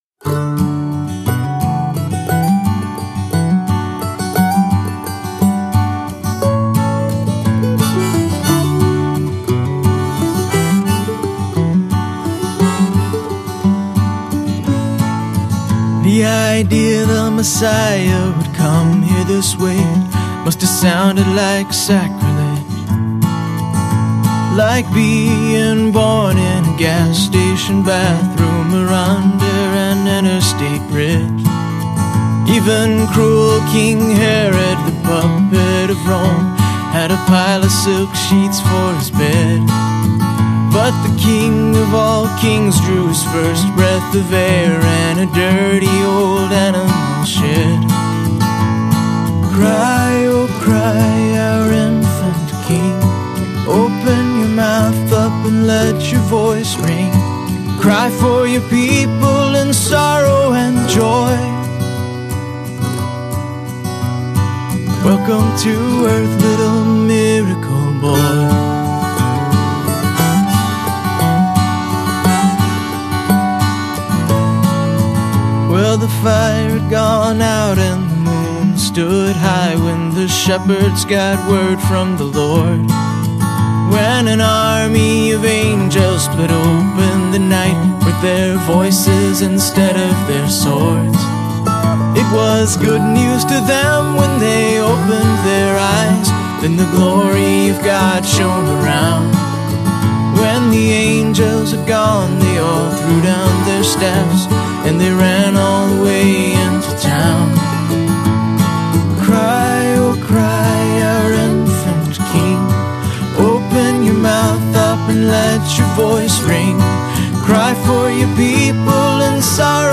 lead vocals, background vocals, acoustic guitars, harmonica
drums, percussion
bass guitar
electric guitars
piano, organ, rhodes, keys
accordian, mandolin, high strung guitar